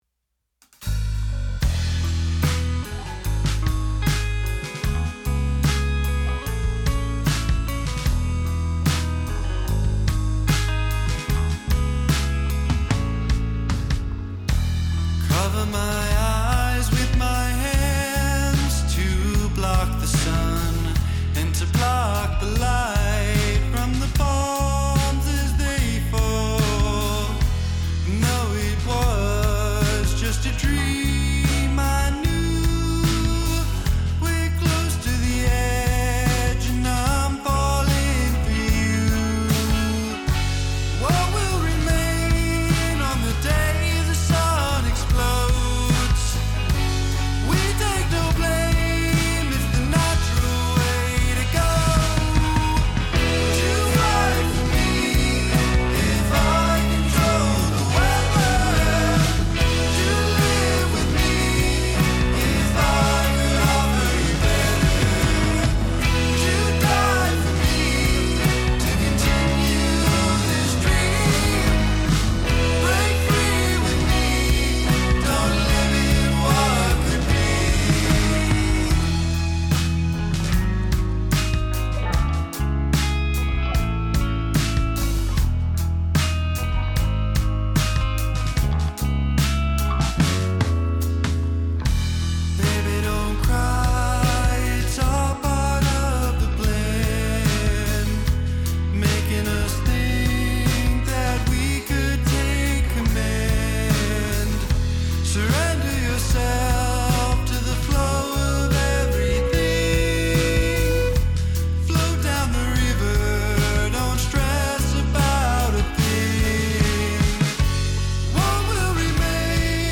dreamier, softer sound